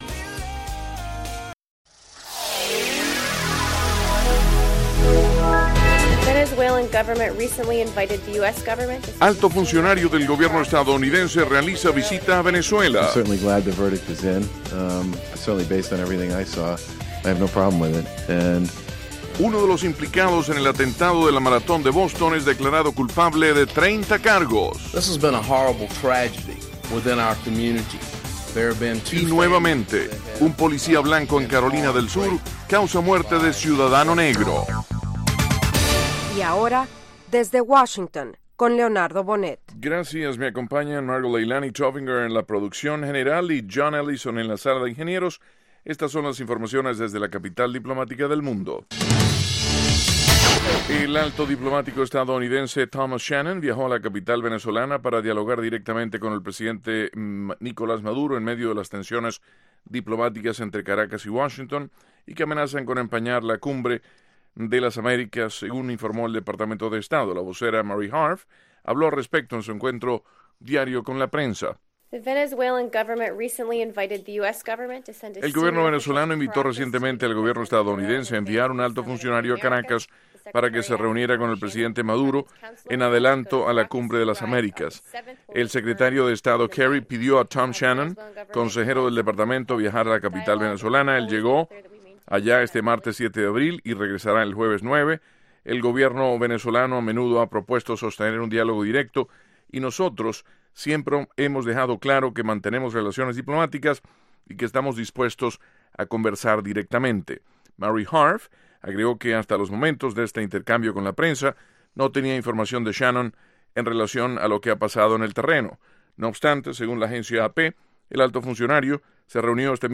Diez minutos de las noticias más relevantes del día, ocurridas en Estados Unidos y el resto del mundo.